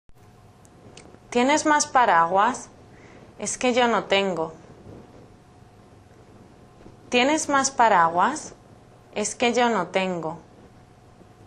・スペイン人女性が、あなたにスペイン語で質問を連発します。
・質問は2度繰り返されます。
今回はスペイン人女性の声です。
余計な日本語や「レッスン１」などのタイトルフレーズもなし。